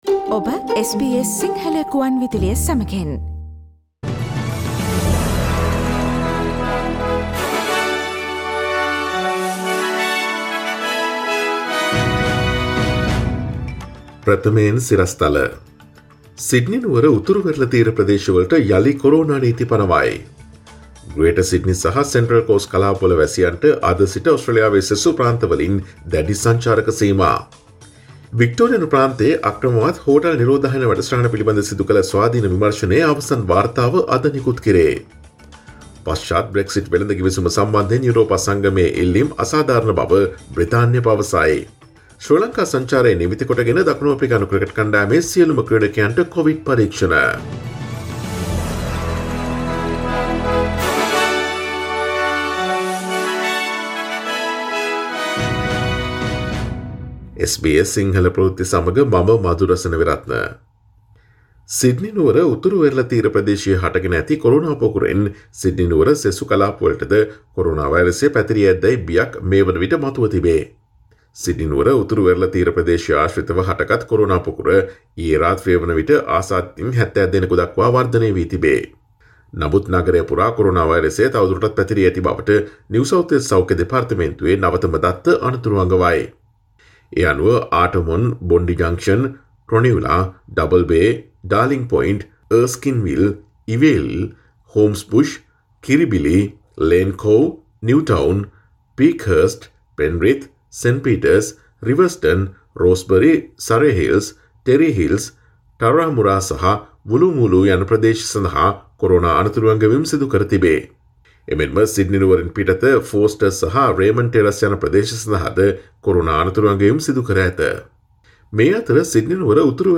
Today’s news bulletin of SBS Sinhala radio – Monday 21 December 2020